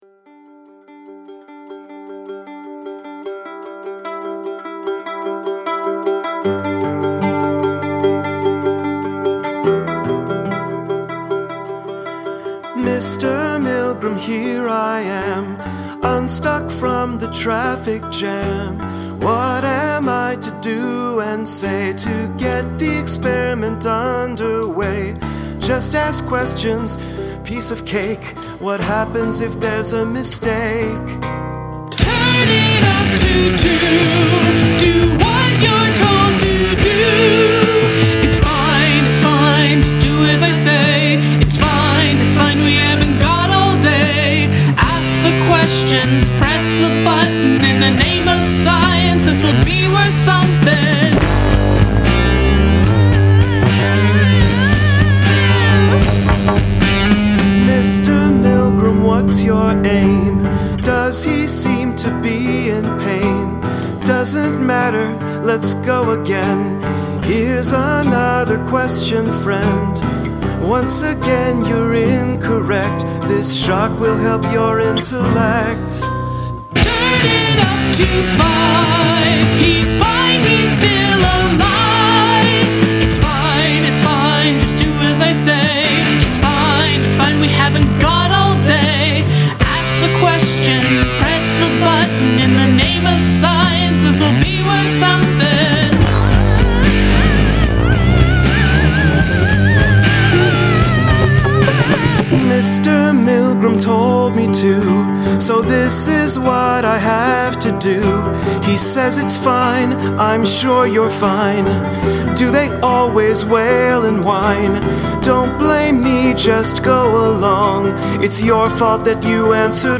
Challenge: Dynamics